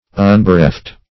Unbereft \Un`be*reft"\, a. Not bereft; not taken away.